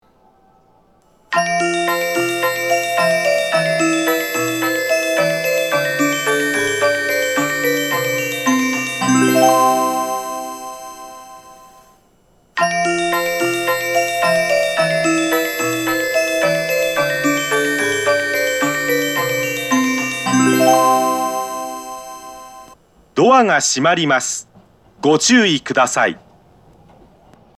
発車メロディー
1・2番線共にメロディーの音量は小さめです。